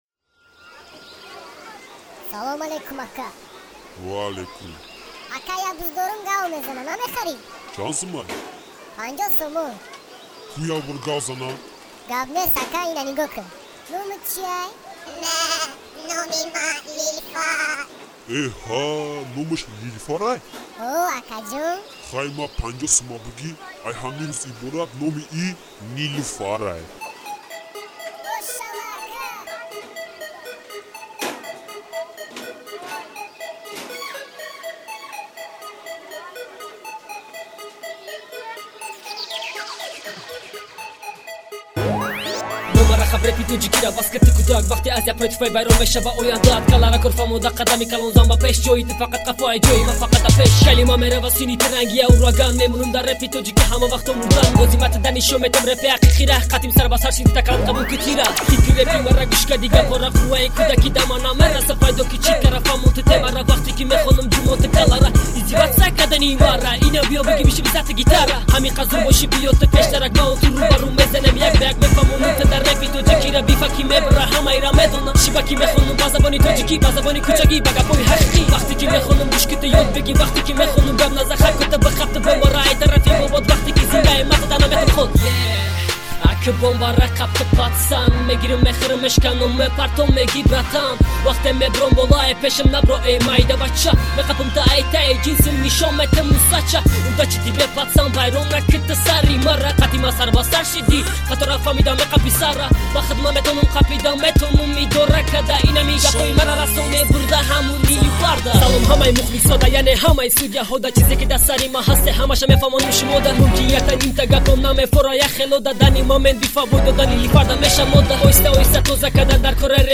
Главная » Каталог mp3 » Рэп / HIP HOP » Tajik Rap